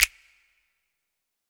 REDD Snap (2).wav